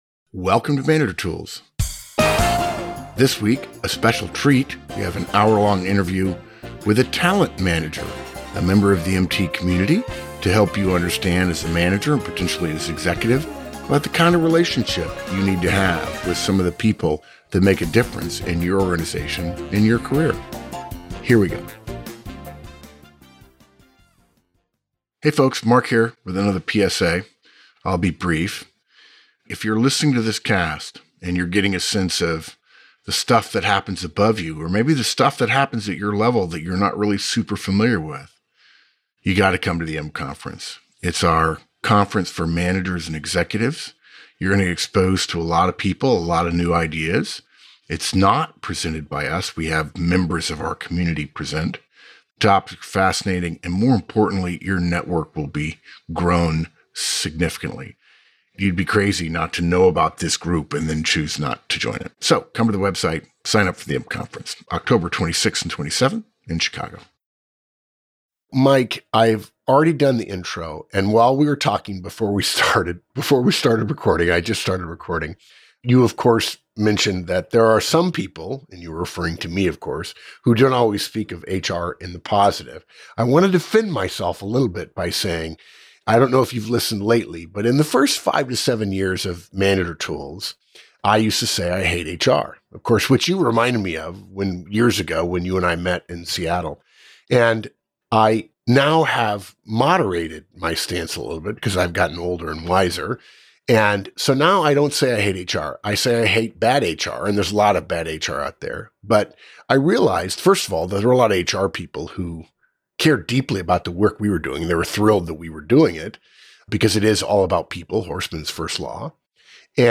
Interview With a Talent Manager | 1Manager Tools